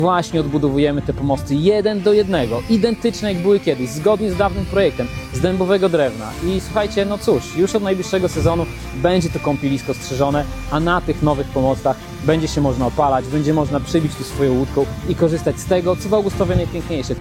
Obiekt nie jest jeszcze gotowy, ale nabiera pierwotnych kształtów – mówi Filip Chodkiewicz, zastępca burmistrza Augustowa.